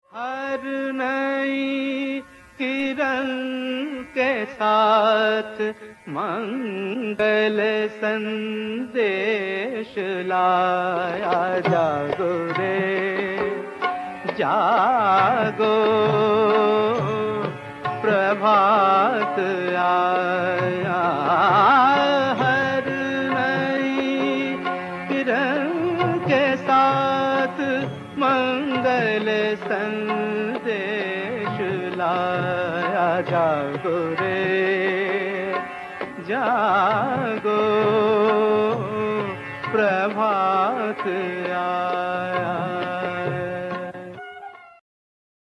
We unveil the filmi tableau.